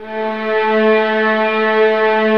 VIOLINS BN-R.wav